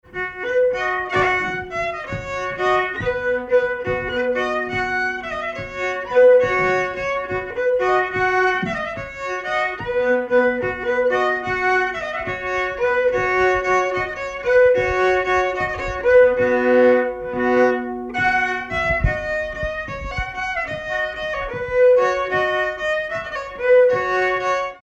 Danse
Pièce musicale inédite